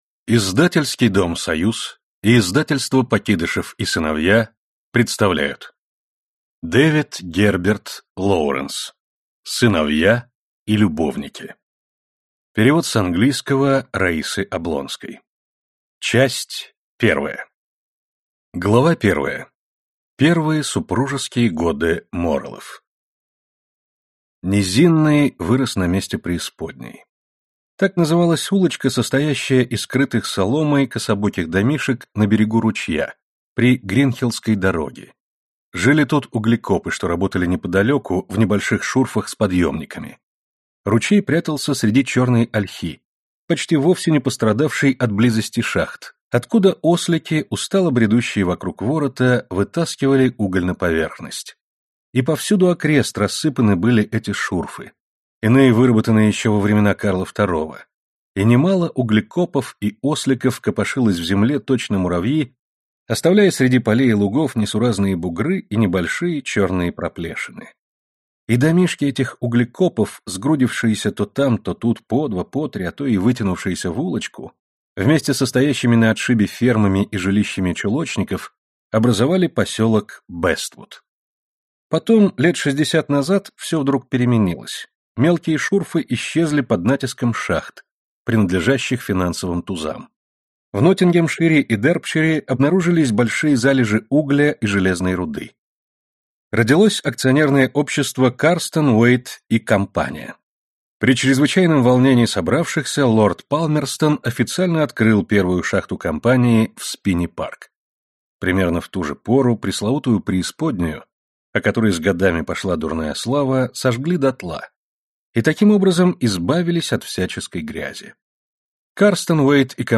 Аудиокнига Сыновья и любовники | Библиотека аудиокниг